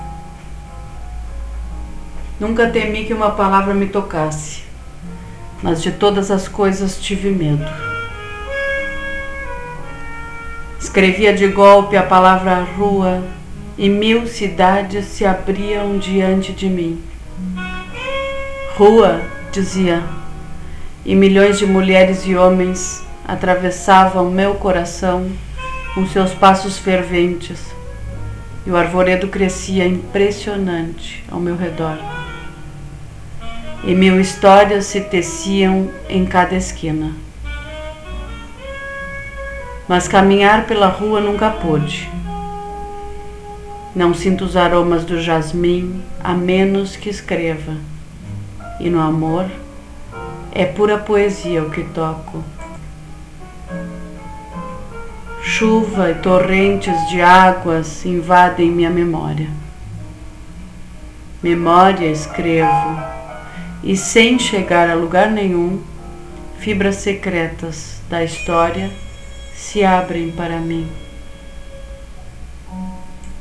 declamacion 62.wav